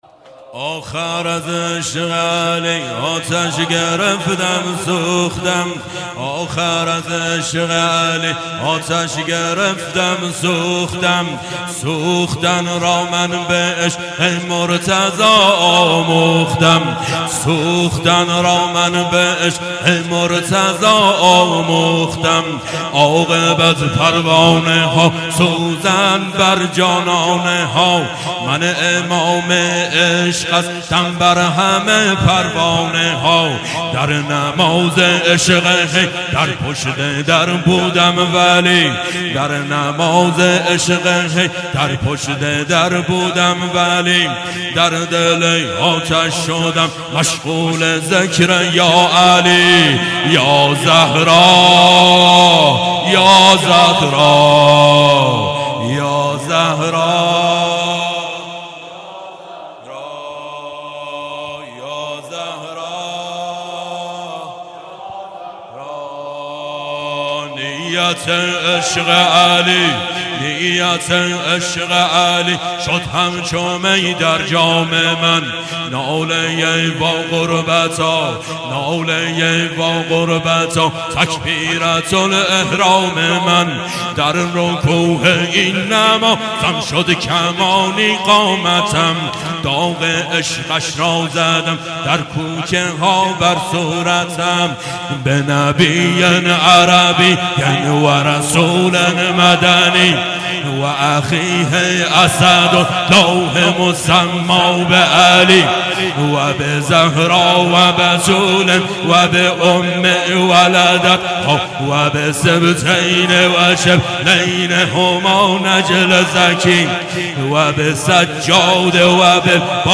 مراسم عزاداری شب اول فاطمیه دوم 1393
شلاقی آخر مجلس